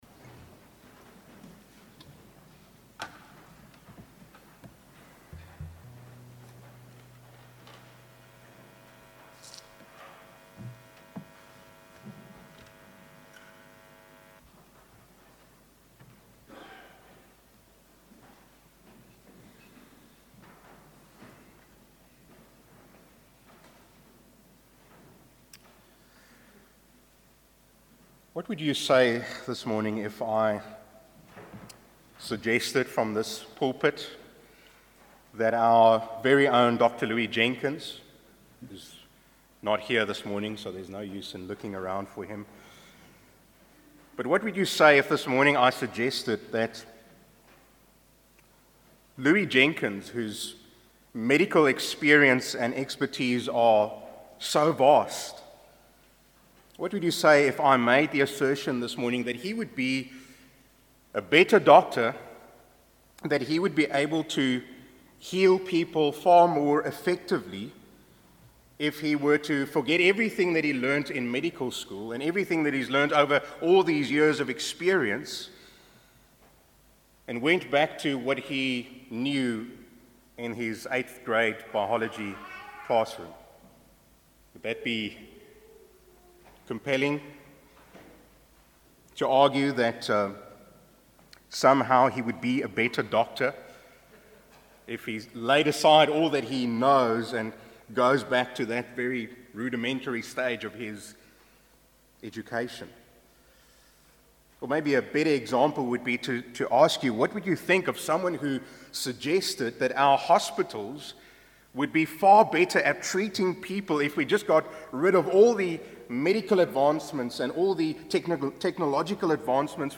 A message from the series "The Gospel in Leviticus."